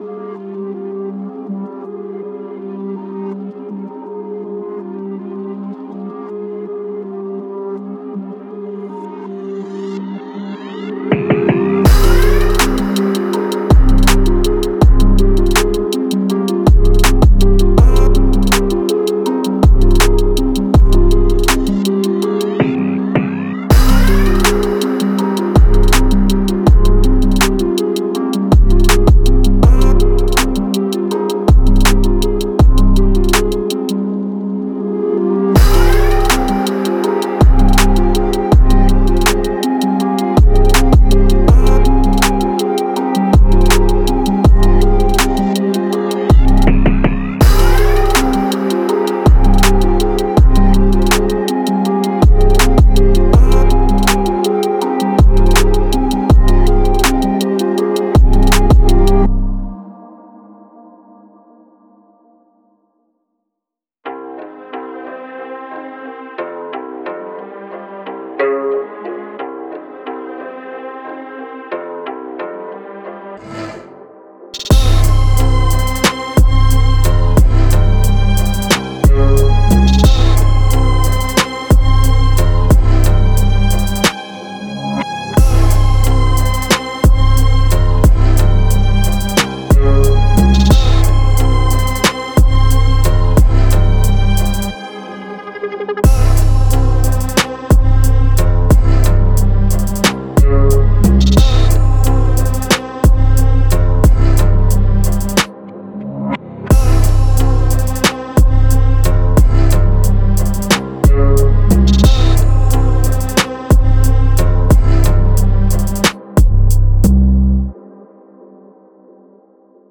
Genre:Hip Hop
メロディにはムーディーでシネマティックな雰囲気があり、作品に感情や緊張感を加えるのに最適です。
デモサウンドはコチラ↓